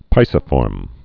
(pīsə-fôrm)